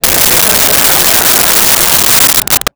Crowd Laughing 04
Crowd Laughing 04.wav